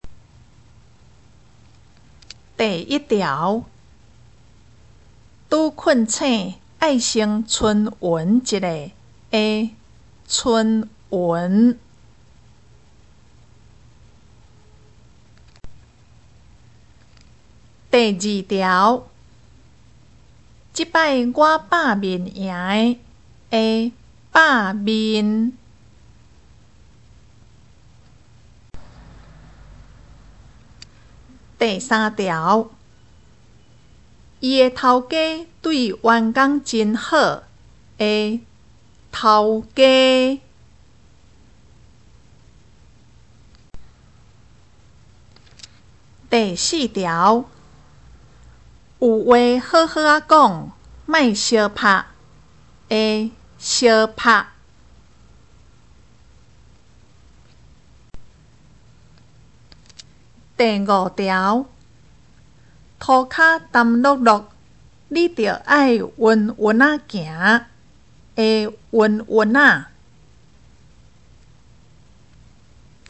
【國中閩南語1】單元評量(2)聽力測驗mp3